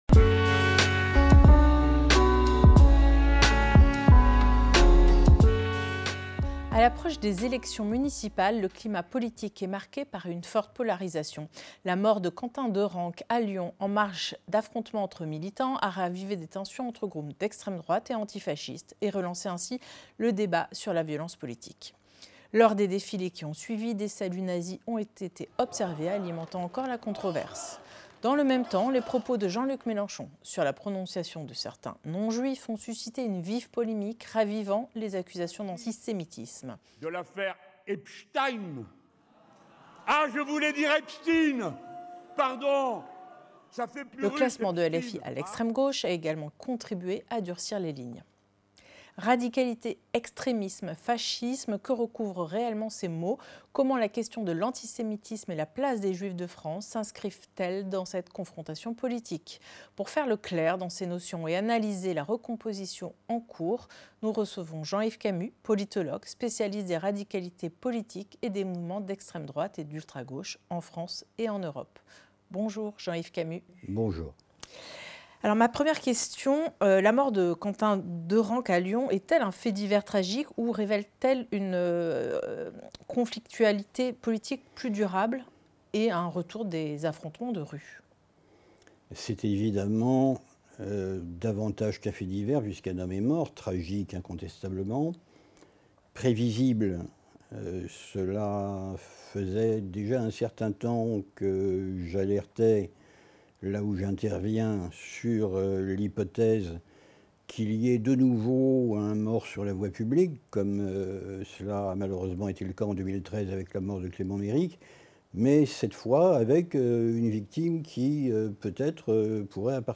Entretien Le seul bouclier des Juifs